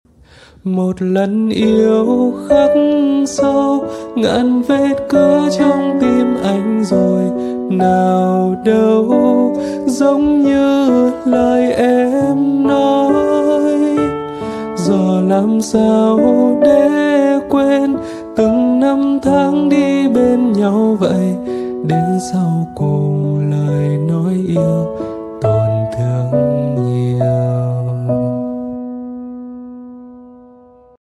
Lofi buồn, sad vibe